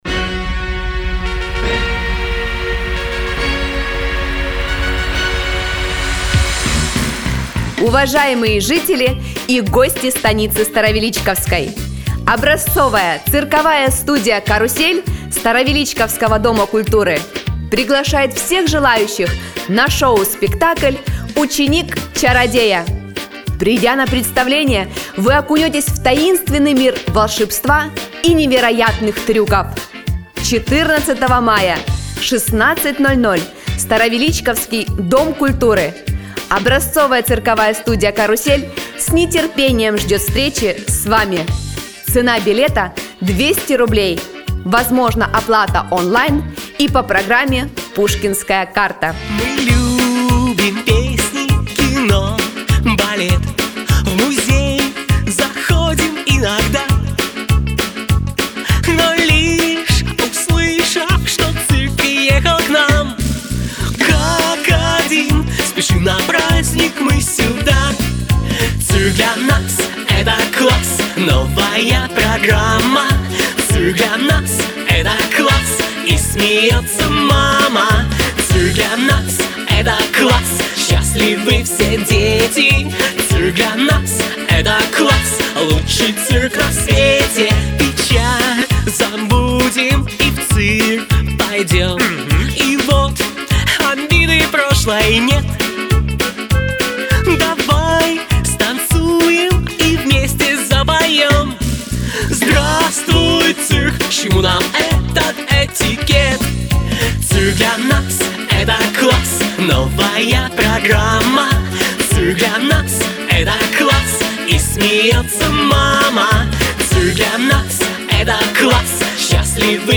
mp3 (7.8 MiB) Объявление Цирк (7.8 MiB)